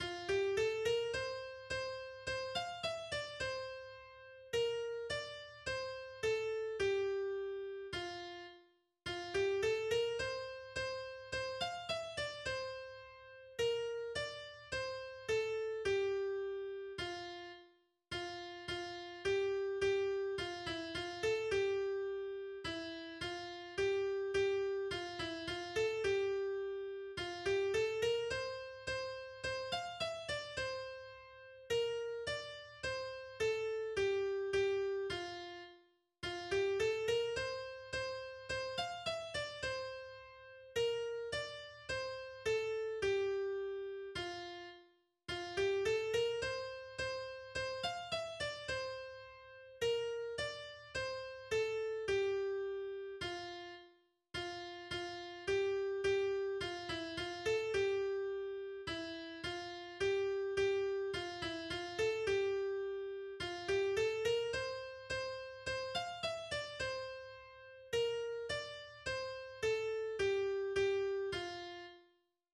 Weihnachtliches Hirtenlied aus Tirol